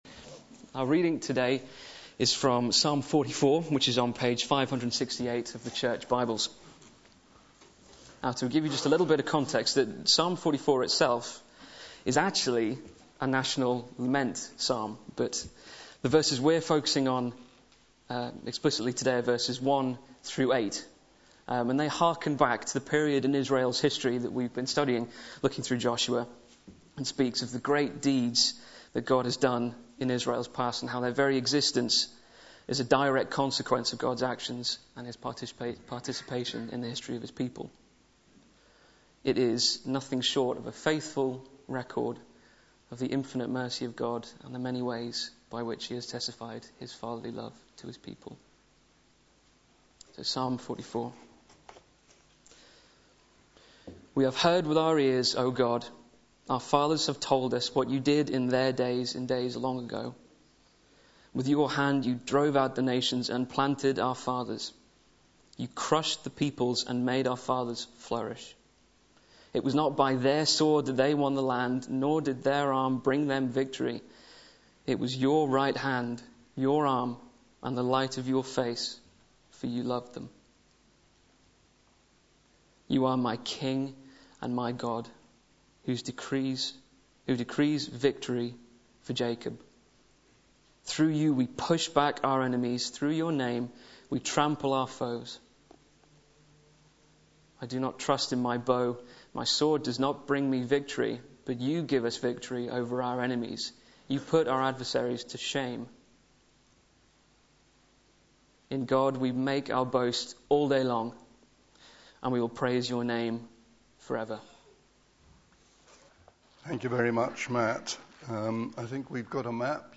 Media for Sunday Service on Sun 03rd Apr 2011 11:00
Series: The Book of Joshua Theme: Faith Unifies Sermon